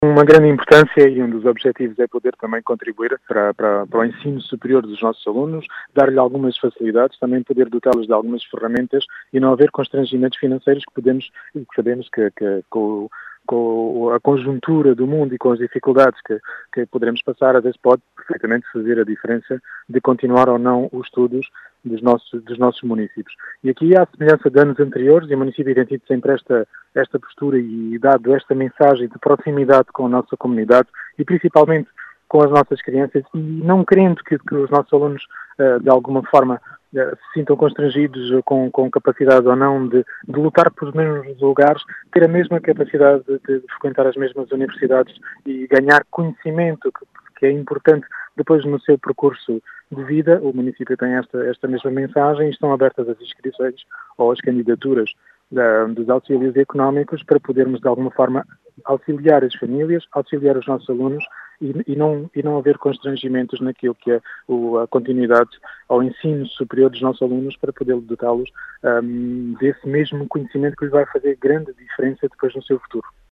As explicações são do presidente da Câmara Municipal de Vidigueira, Rui Raposo, que quer contribuir para ultrapassar alguns “constrangimentos financeiros” aos estudantes do ensino superior do concelho.